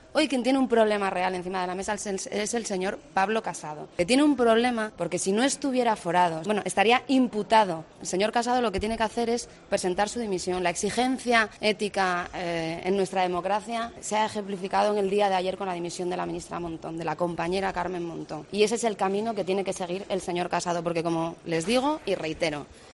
La portavoz del PSOE en el Congreso, Adriana Lastra, apela al Presidente de PP: "Lo que hizo Montón ayer es indicarle el camino".
"El presidente del Gobierno no tiene nada que ocultar, está doctorado y tiene su tesis, que está como todas en el registro de la universidad", ha dicho en los pasillos del Congreso.